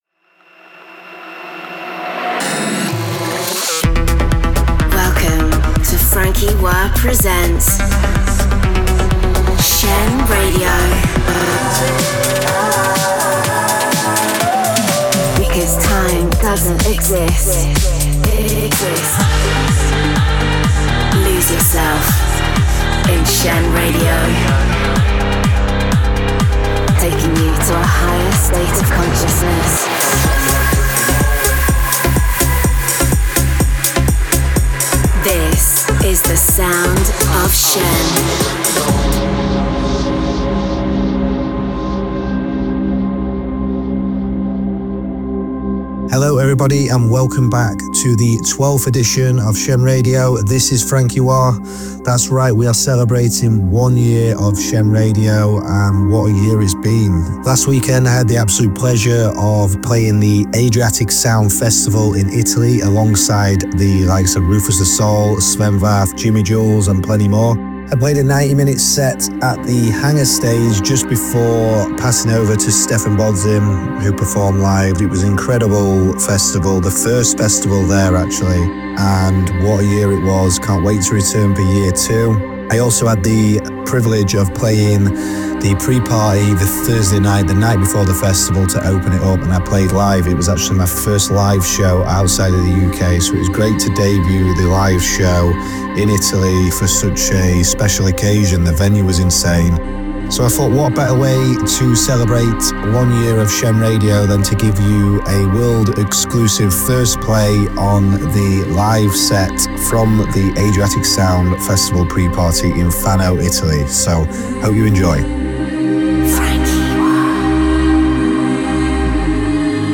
conceptual, cutting-edge, progressive sounds